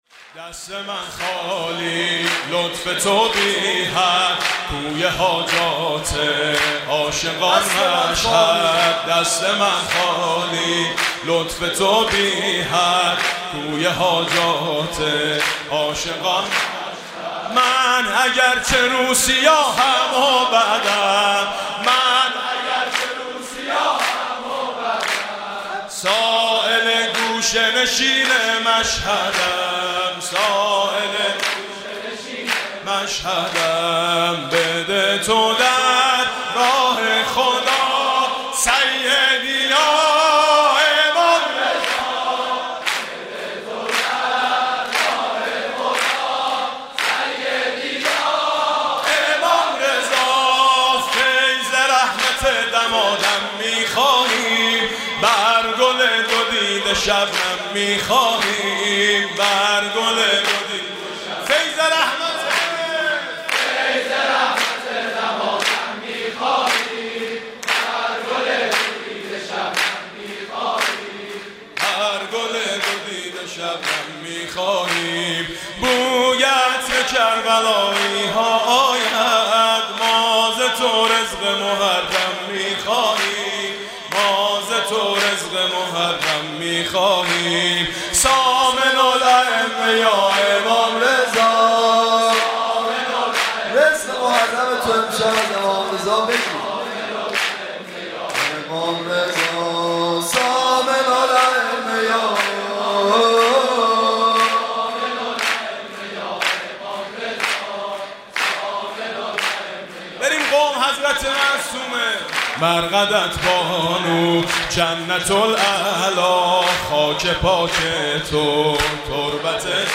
«میلاد امام رضا 1392» سرود: دست من خالی لطف تو بی حد